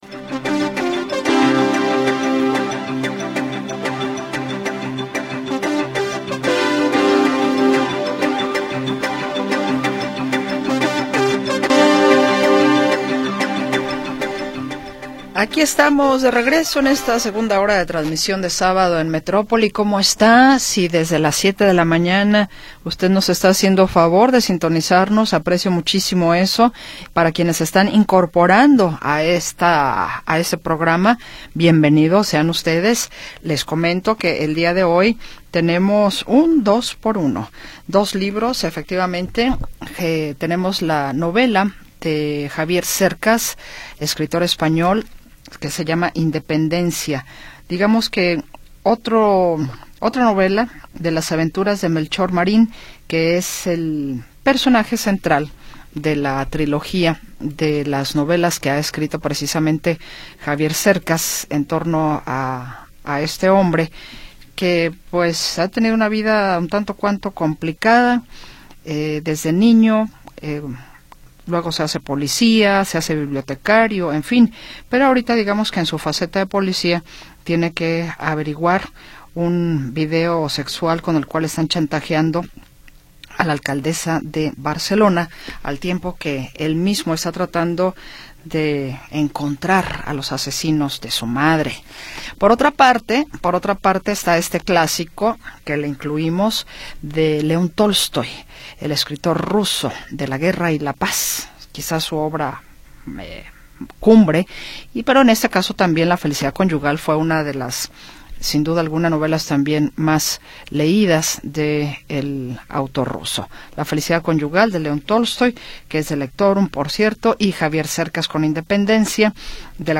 20 de Septiembre de 2025 audio Noticias y entrevistas sobre sucesos del momento